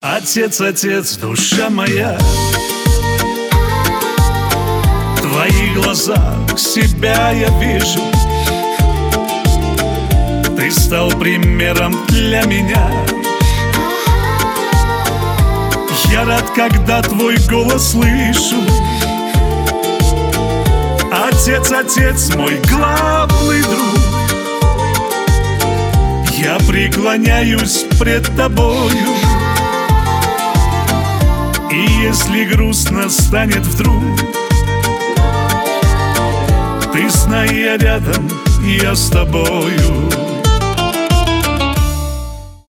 поп
кавказские